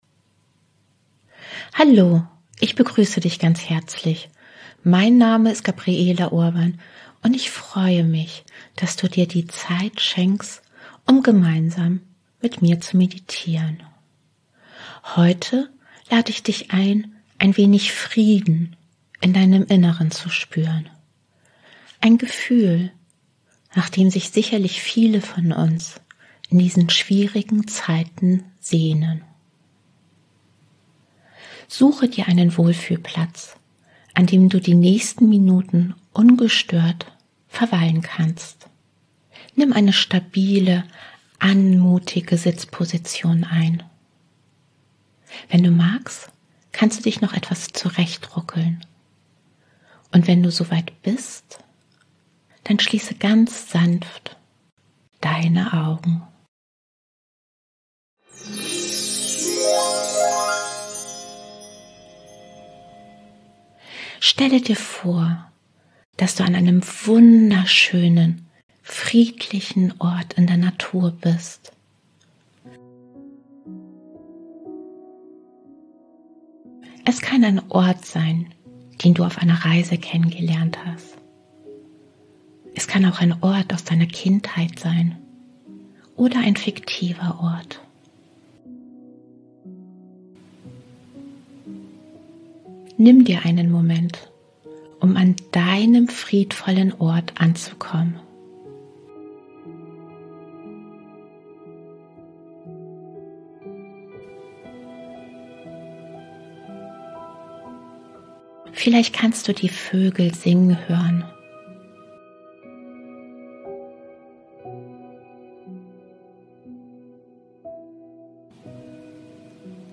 Diese geführte Fantasiereise für Erwachsene, die zugleich auch eine Körperreise ist, soll dir vor allem dabei helfen, loszulassen – und mehr Zuversicht, innere Wärme und Frieden tief in deinem Inneren zu spüren.